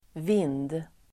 Uttal: [vin:d]